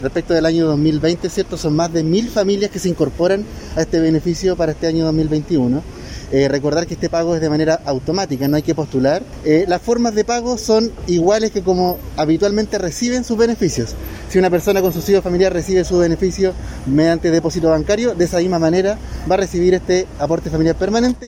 Al respecto, el director regional del Instituto de Previsión Social, Joaquín Núñez, dijo que el aporte llegará a más 127 mil familias, a través de sus cuentas bancarias o de forma presencial.